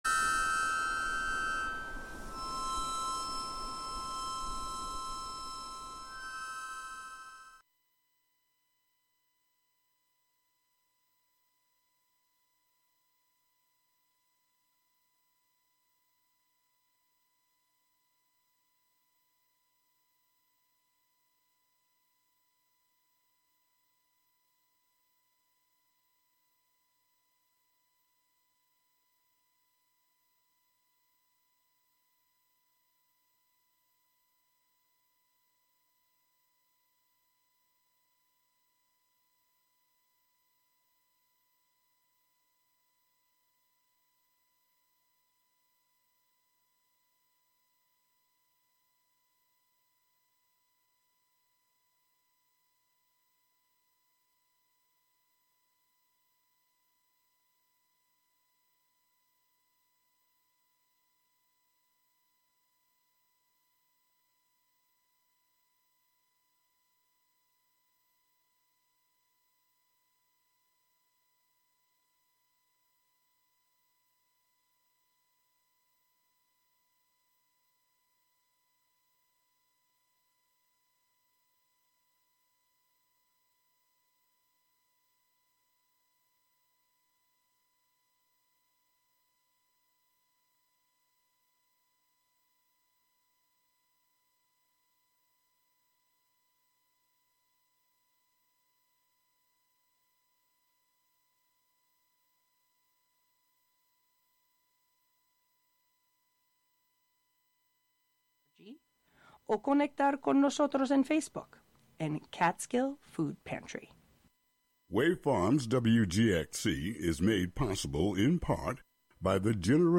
What started as a photography series then video series, "Uncontaminated Sound" is a conversation series for radio that follows comedians, musicians, fine artists, and actors with the primary objective to gain a particular insight into their creative processes which can only be fully unravelled by truly going behind the scenes.
These features offer listeners real, raw, and authentic conversations.